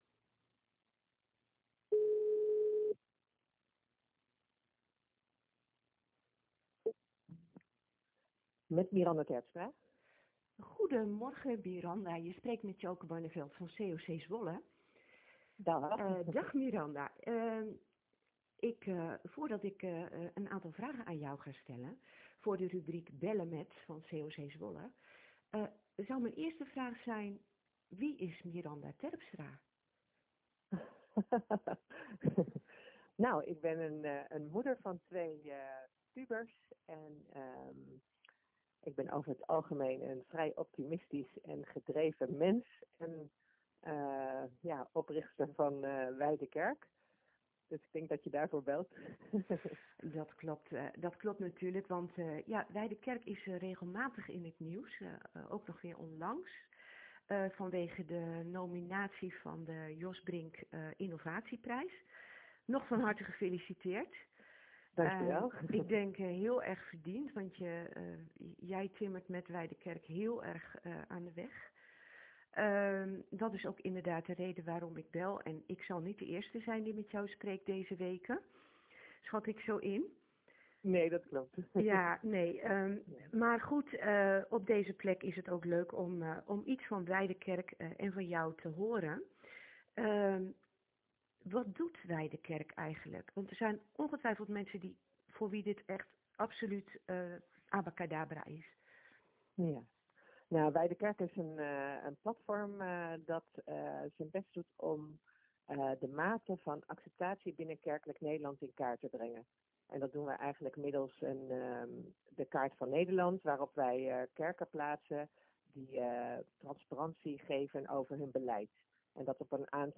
Een openhartig gesprek.